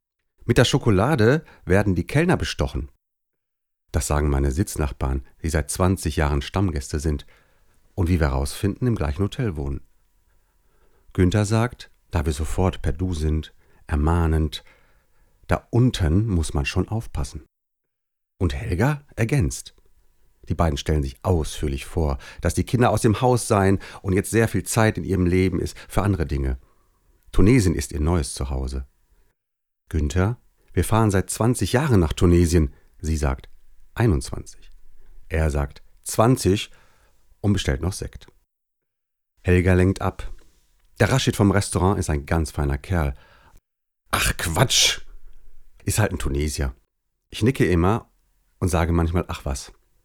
Sprechprobe: Sonstiges (Muttersprache):
hoerbuch_demo_v01_0.mp3